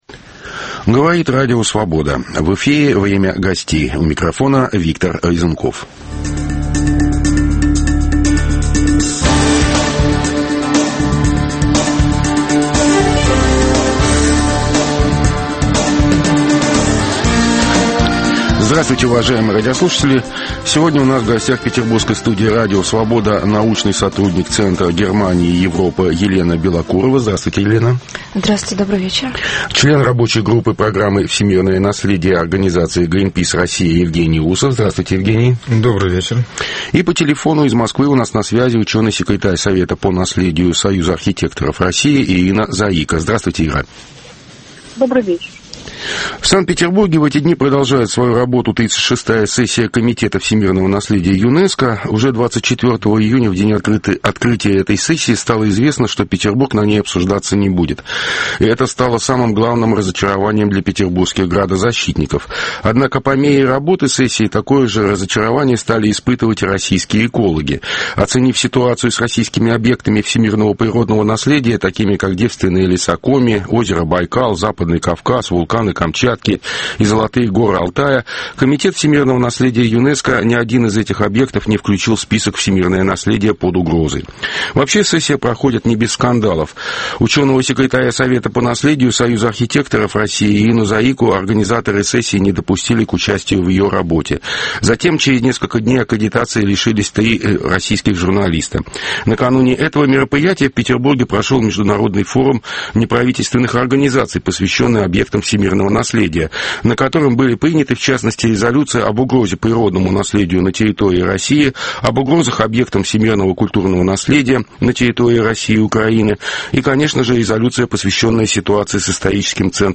Почему российские градозащитники и экологи недовольны итогами 36-ой сессии ЮНЕСКО, проходящей в эти дни в Петербурге? Обсуждают: научный сотрудник Центра Германии и Европы